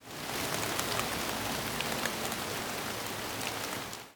rain8.ogg